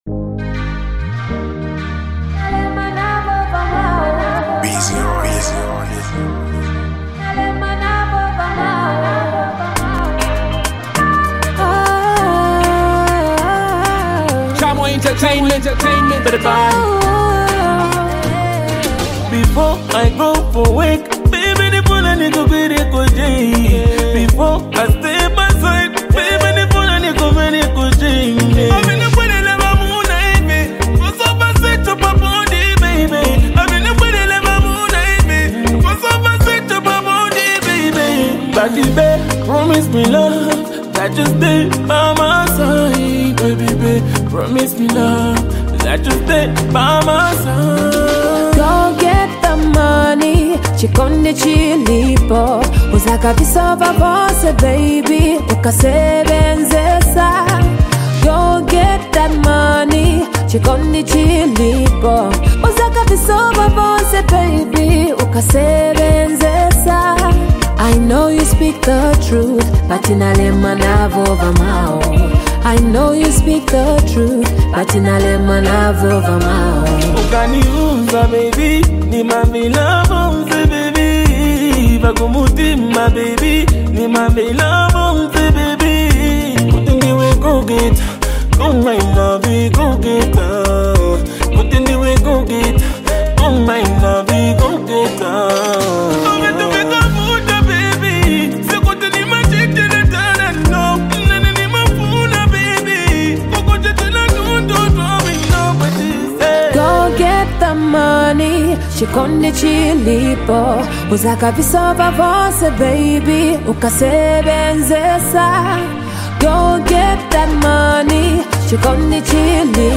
heavyweight female artist
mellow banger